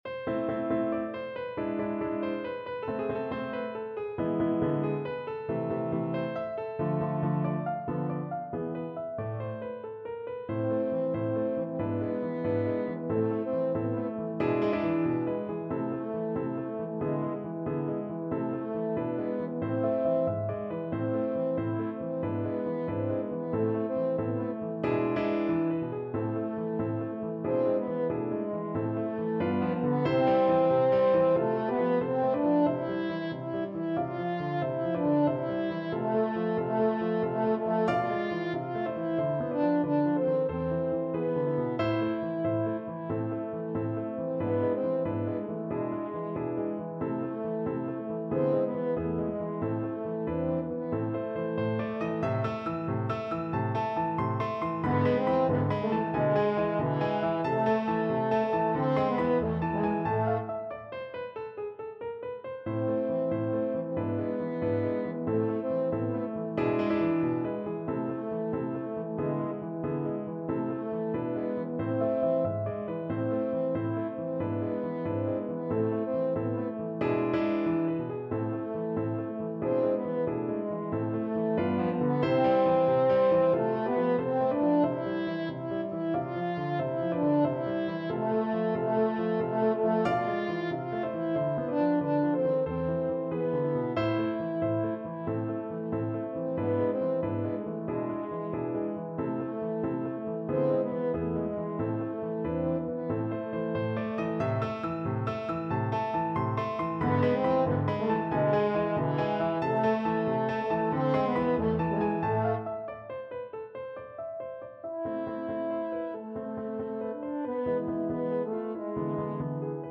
French Horn
Allegretto affettuoso =92
A minor (Sounding Pitch) E minor (French Horn in F) (View more A minor Music for French Horn )
Classical (View more Classical French Horn Music)